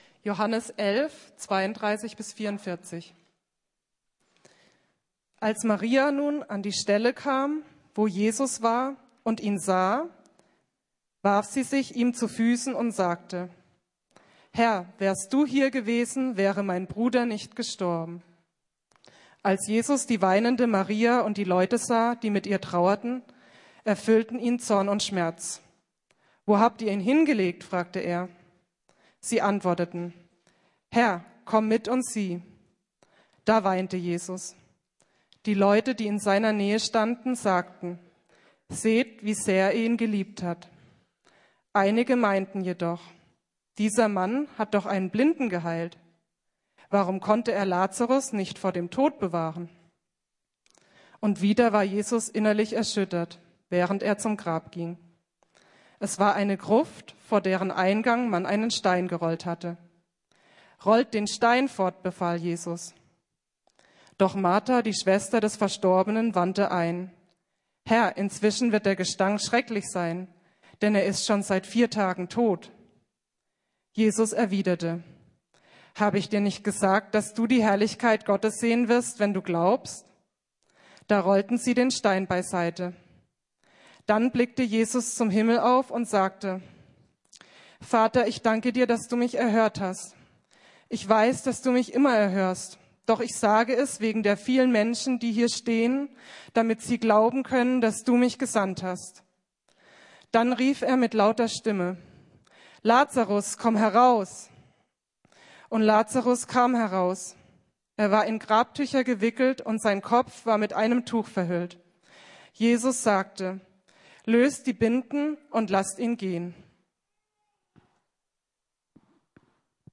Jesus ist größer als meine Trauer ~ Predigten der LUKAS GEMEINDE Podcast